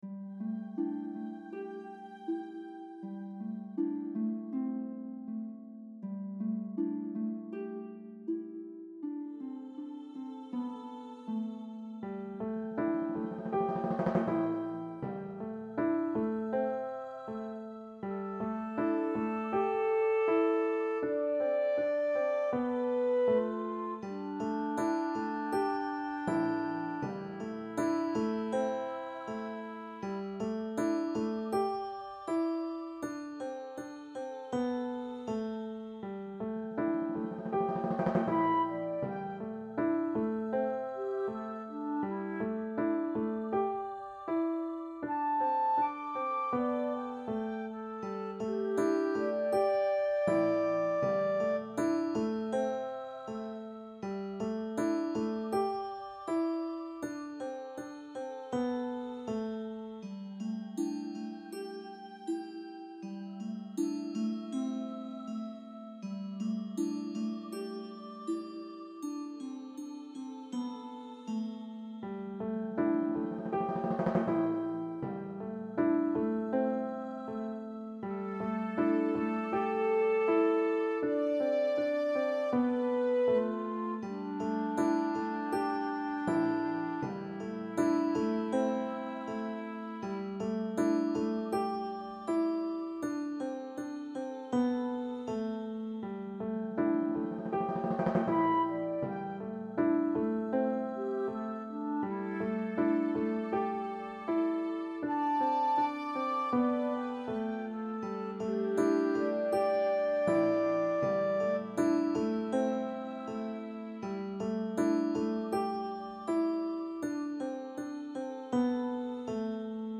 The music was beautiful and expressive.